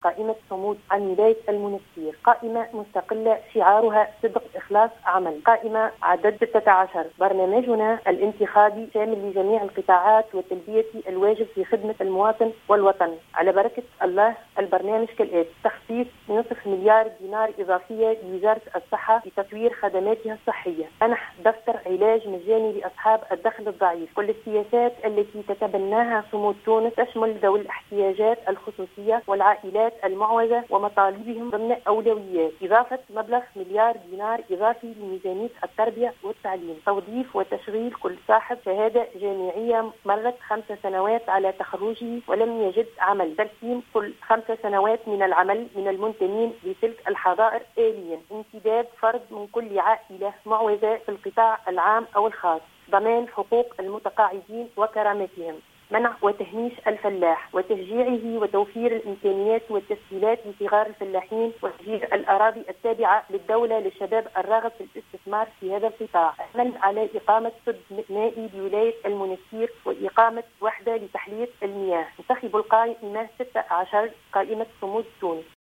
في تصريح للجوْهرة أف أم